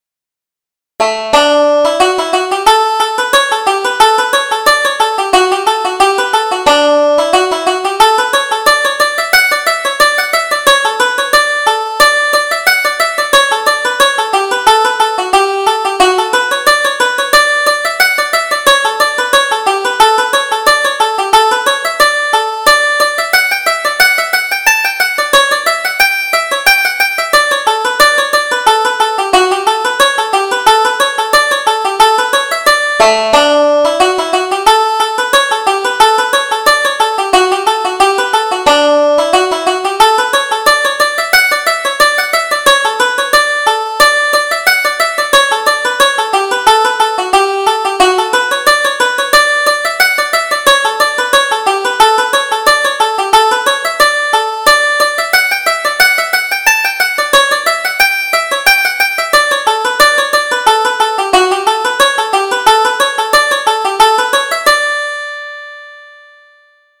Reel: The Gooseberry Bush